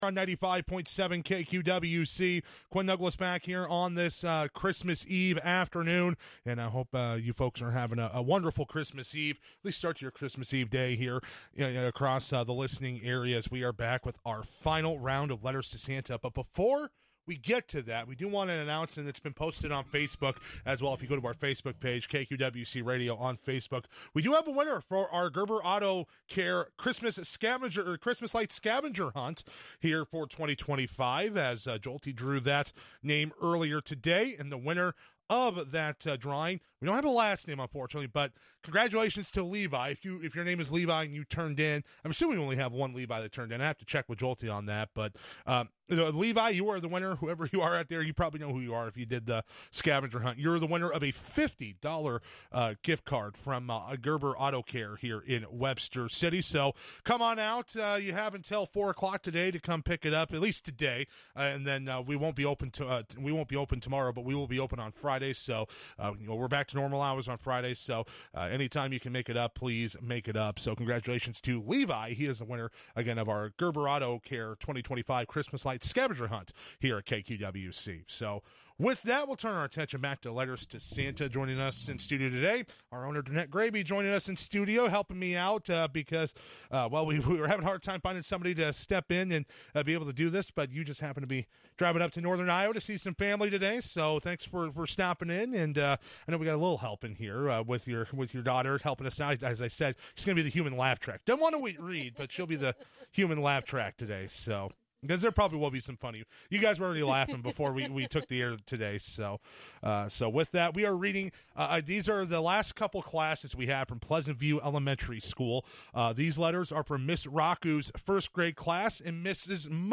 Readers